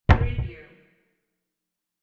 Cabinet Door Close Wav Sound Effect #2
Description: The sound of a wooden cabinet door being closed
Properties: 48.000 kHz 16-bit Stereo
Keywords: cabinet, door, close, closing, shut, shutting, cupboard, wood, wooden
cabinet-door-close-preview-2.mp3